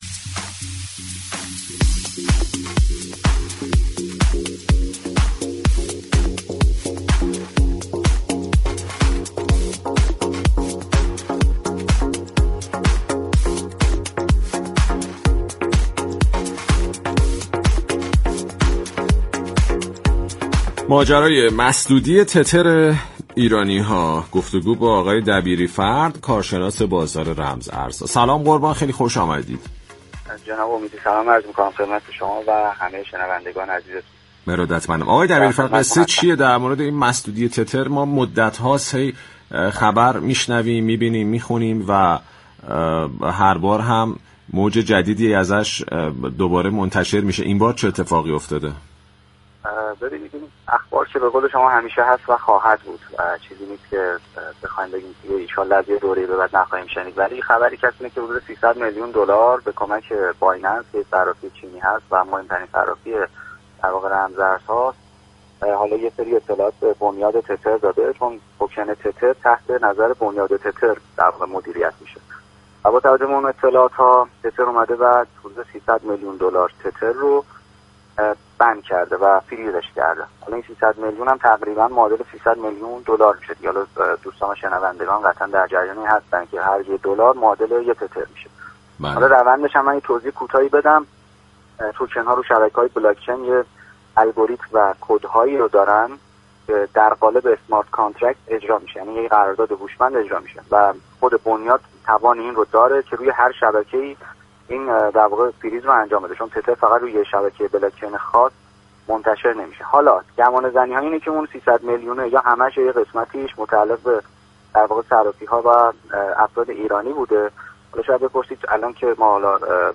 در گفت و گو با برنامه «بازار تهران»